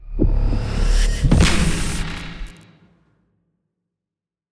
eldritch-blast-005.ogg